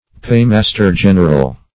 Paymaster-general \Pay"mas`ter-gen"er*al\